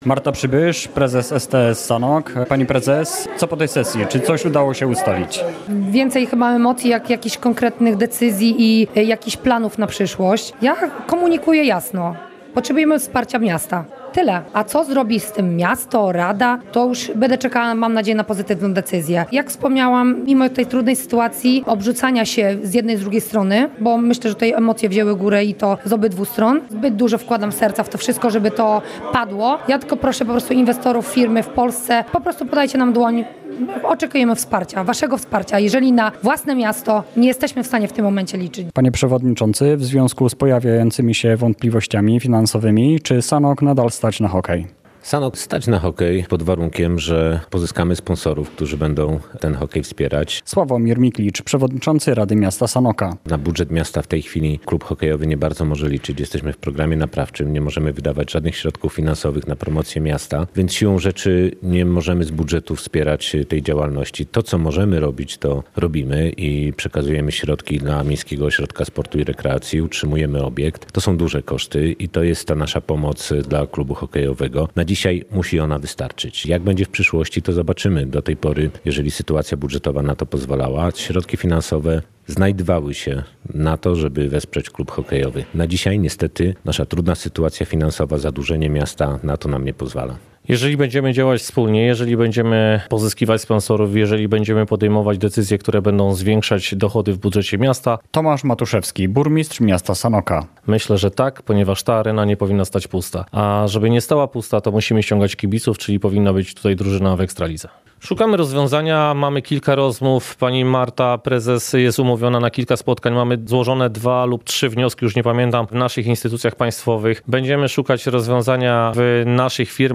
Rada Miasta Sanoka zwołuje nadzwyczajną sesję • Relacje reporterskie • Polskie Radio Rzeszów